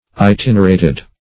& p. p. Itinerated ([-i]*t[i^]n"[~e]r*[=a]`t[e^]d); p. pr.
itinerated.mp3